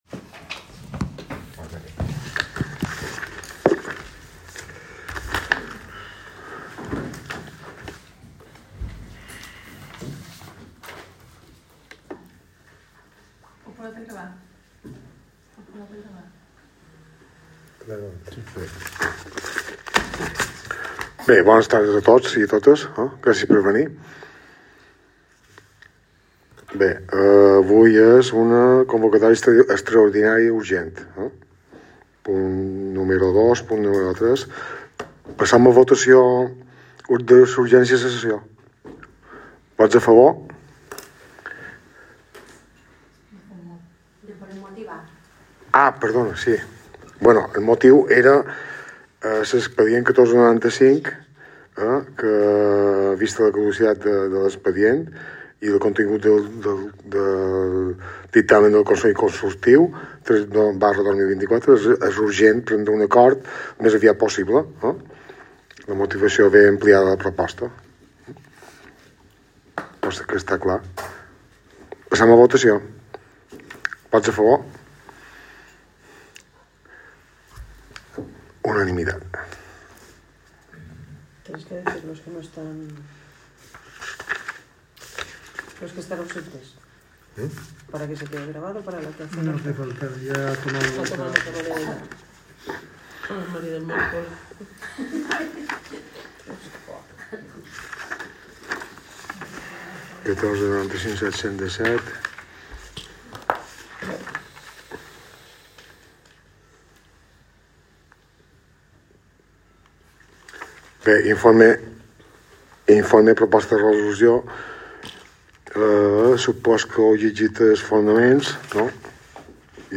Sessió del Ple Extraordinari i urgent de l'Ajuntament de Bunyola que es celebrarà el proper dijous dia 19 de febrer a les 19.00 hores, al Saló de Sessions de la Casa de la Vila.